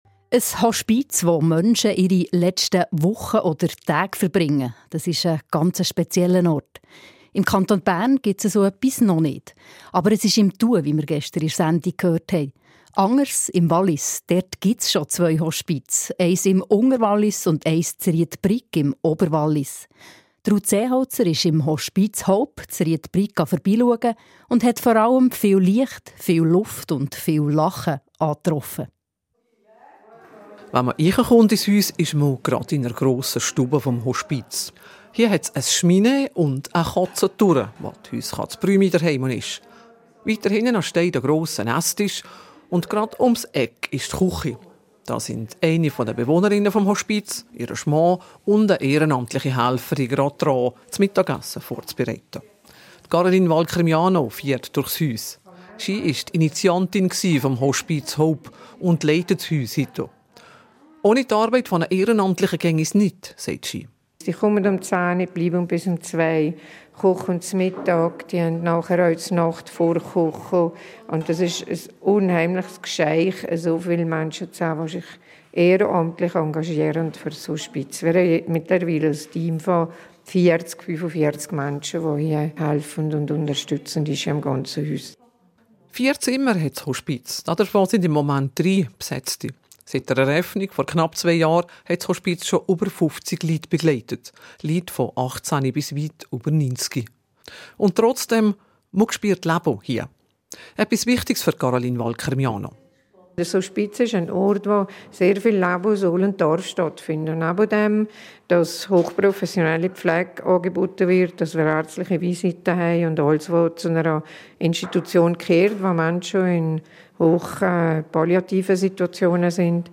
04.03.2026 SRF Regionaljournal Bern Freiburg Wallis: Leben am Ende – eine Reportage aus dem Sterbehospiz Oberwallis
Hospiz-Hope-Ried-Brig-Reportage-Radio-SRF.mp3